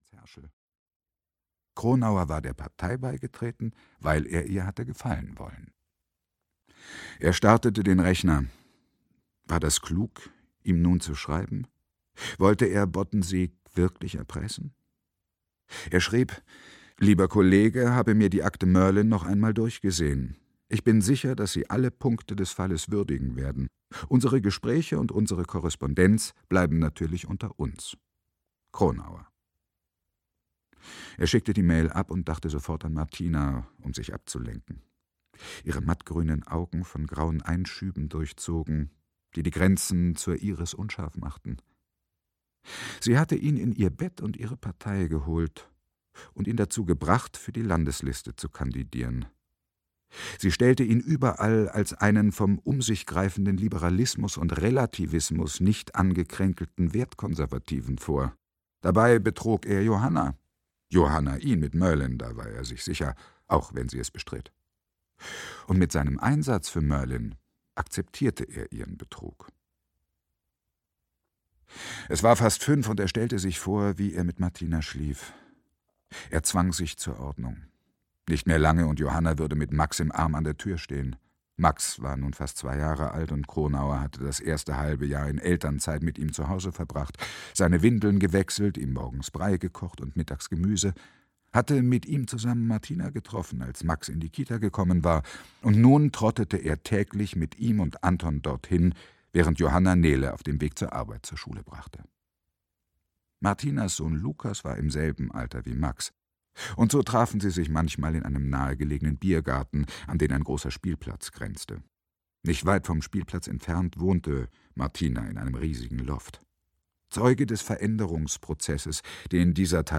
Wenn das Land still ist - Carsten Kluth - Hörbuch - Legimi online